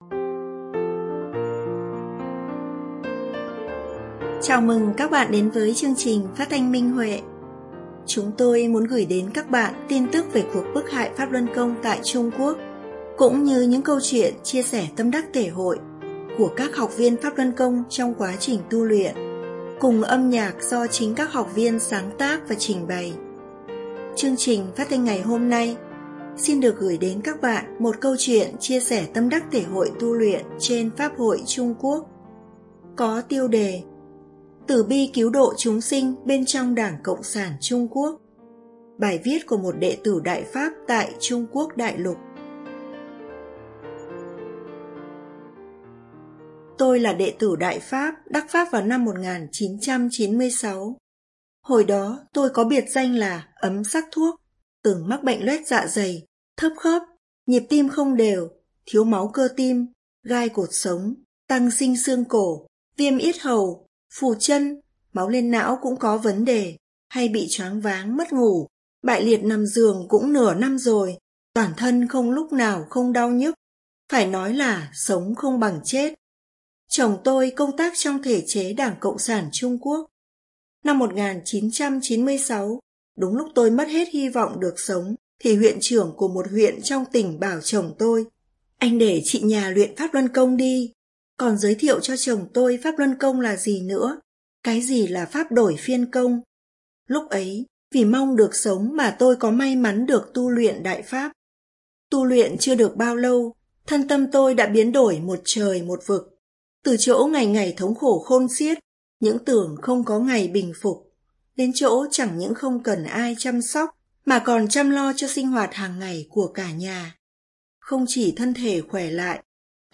Chương trình phát thanh số 732: Bài viết chia sẻ tâm đắc thể hội trên Minh Huệ Net có tiêu đề Từ bi cứu độ chúng sinh bên trong ĐCSTQ, bài viết của đệ tử Đại Pháp tại Trung Quốc.